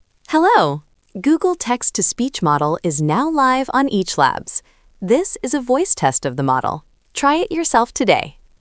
Transformer du texte en discours naturel avec la technologie avancée d'IA de Google
google-text-to-speech-output.wav